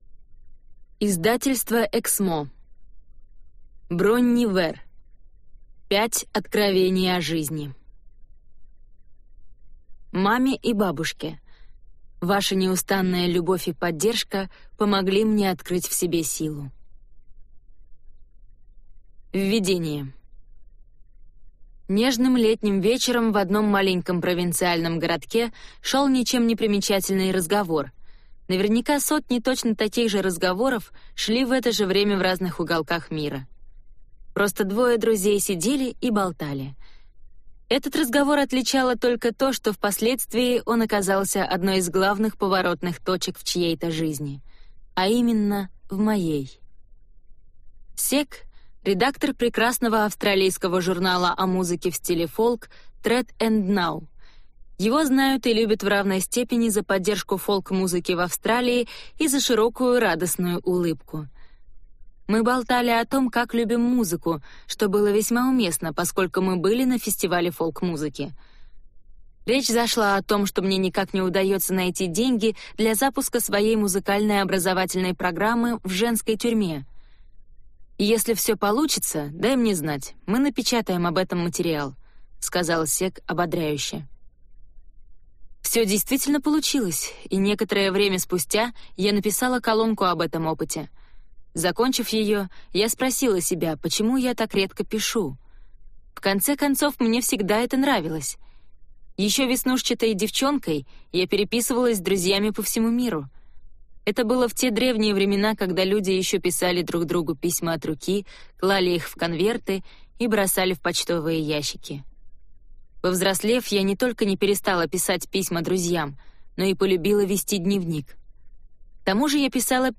Аудиокнига Пять откровений о жизни | Библиотека аудиокниг